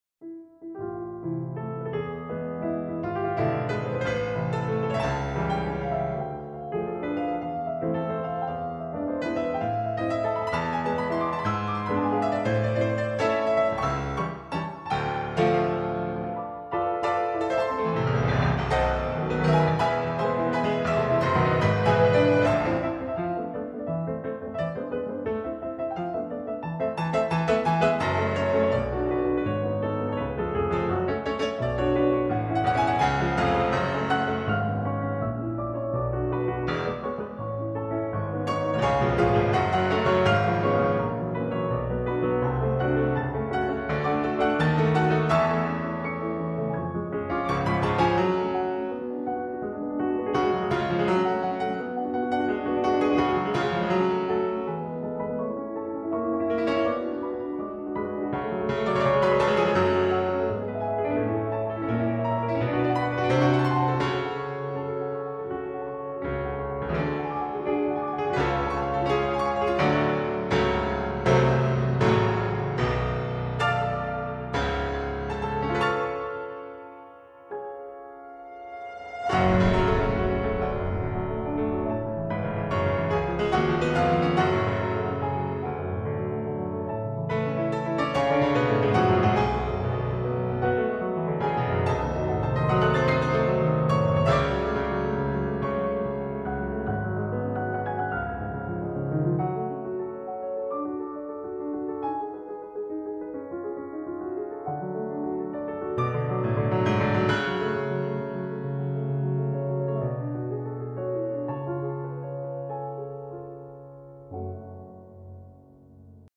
waltz variation
Bass note octaves
inner voicing right hand
6/8 variation